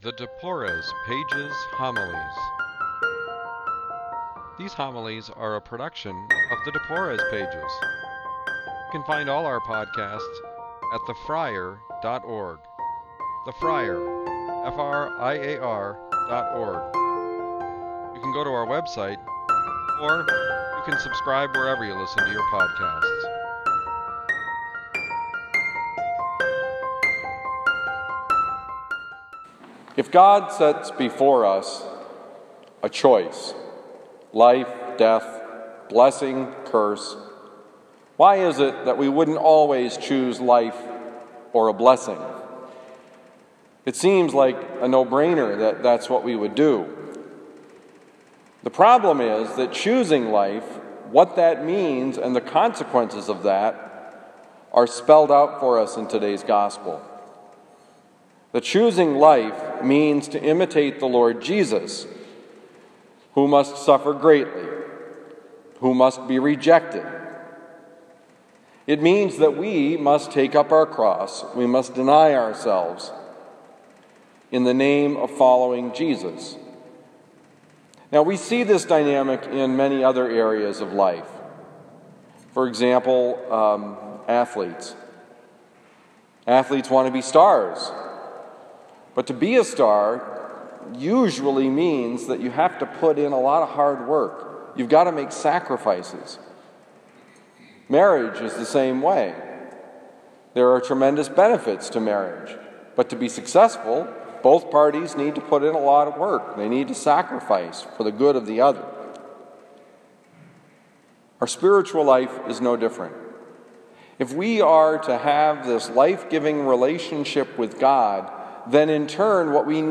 Homily given at Christian Brothers College High School, Town and Country, Missouri